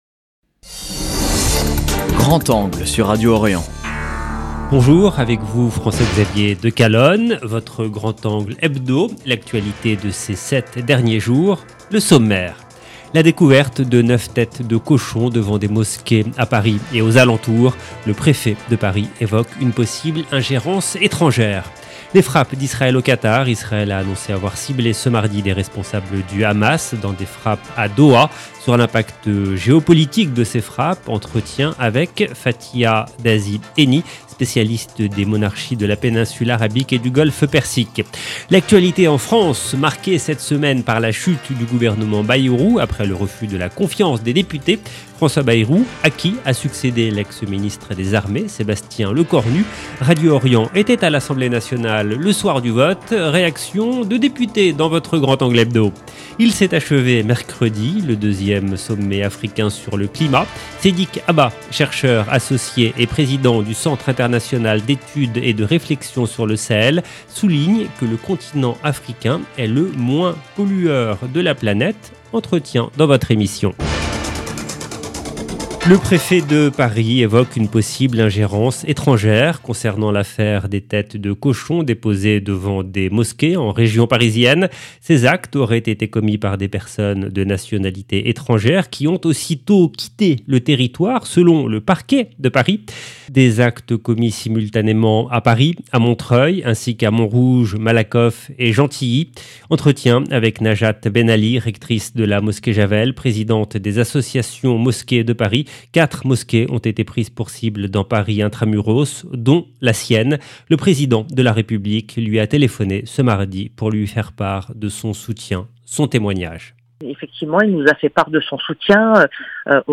Radio Orient était à l’Assemblée nationale le soir du vote. Réactions de députés dans votre Grand angle hebdo.